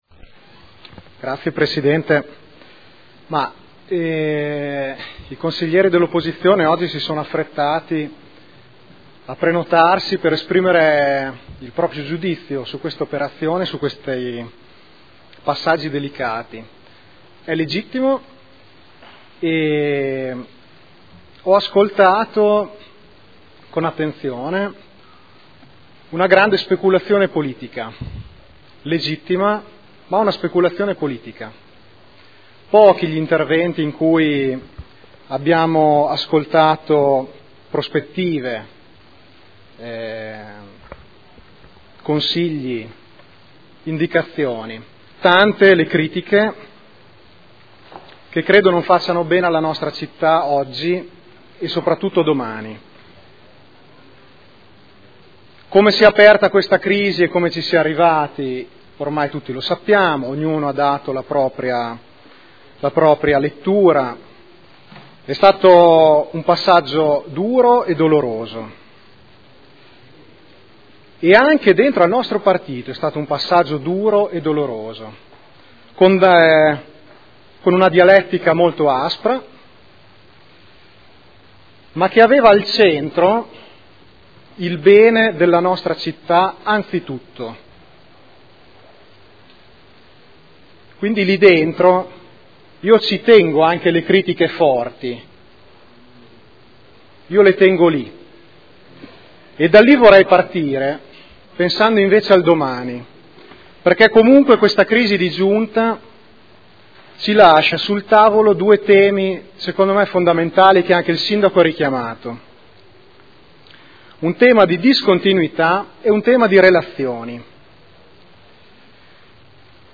Seduta del 23/04/2012. Dibattito su comunicazione del Sindaco sulla composizione della Giunta.